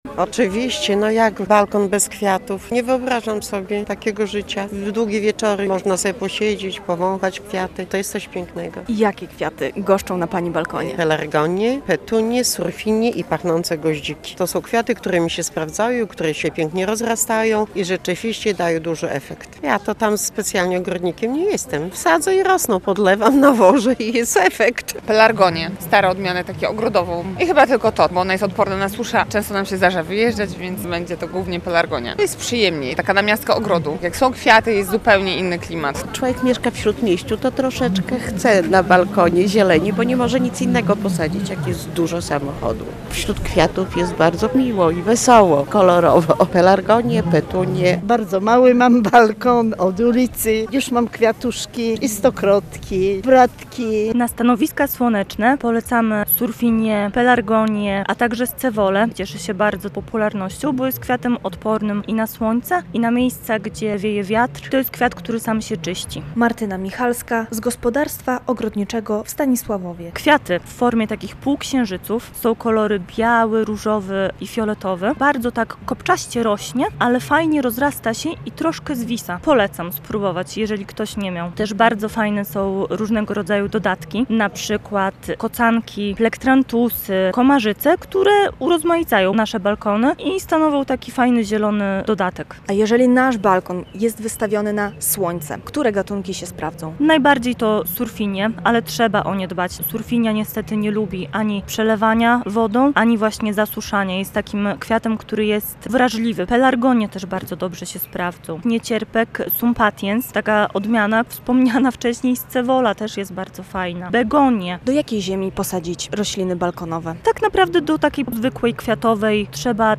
Rośliny balkonowe - relacja